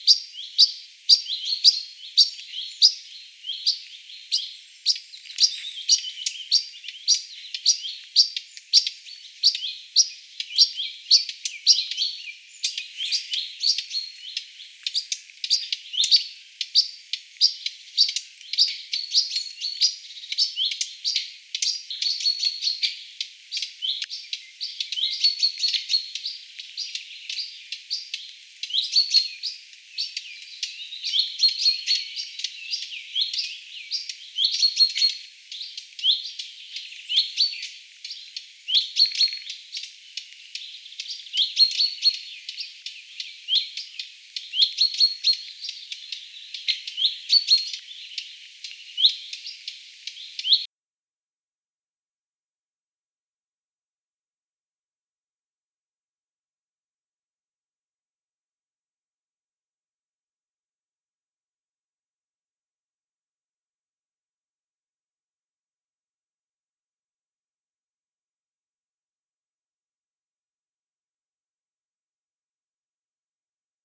hernekerttuemojen huomioääntelyä
Hernekerttujen tsak-äänet ovat tuollaisia pehmeitä?
hernekerttuemojen_huomioaantelya_kirjosieppo.mp3